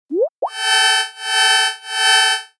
Teams 警报.mp3